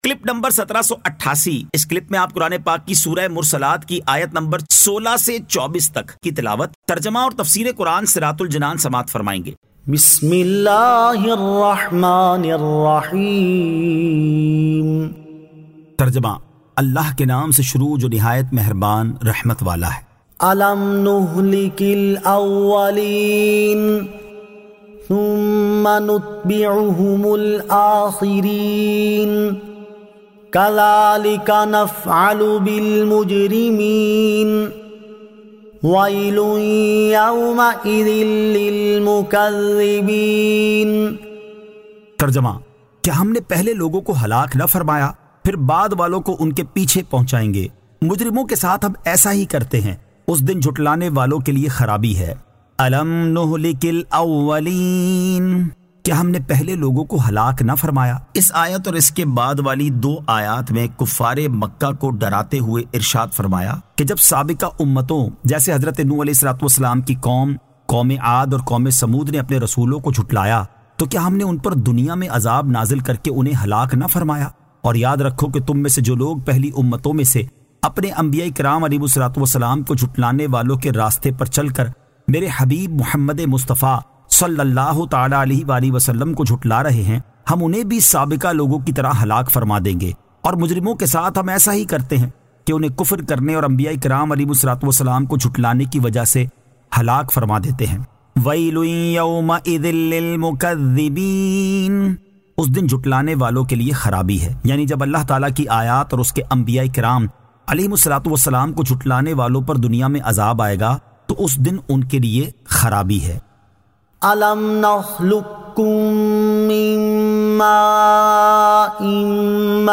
Surah Al-Mursalat 16 To 24 Tilawat , Tarjama , Tafseer